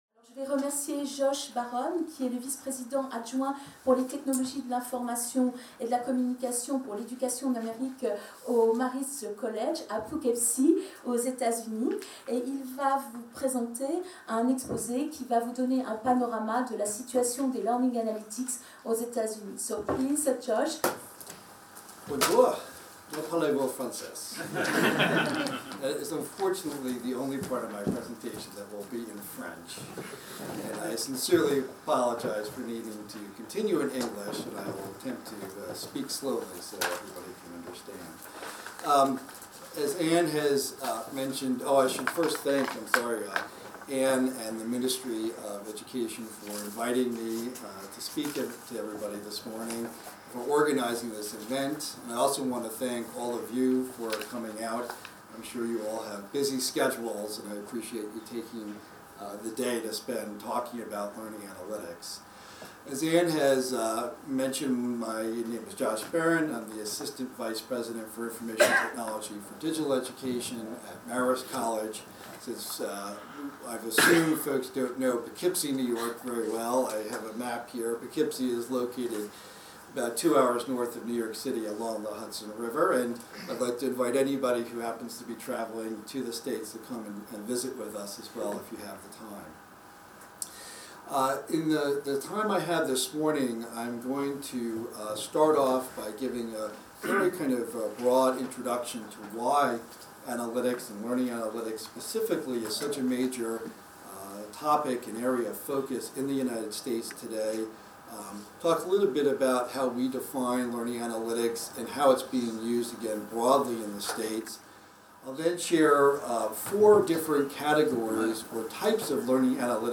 Séminaire du 8 décembre 2015 dédié aux "Learning Analytics", organisé avec le soutien du MENESR. Des experts nationaux et internationaux donnent une synthèse du sujet, afin d'ouvrir une discussion avec les participants sur les actions à entreprendre, les domaines à investiguer...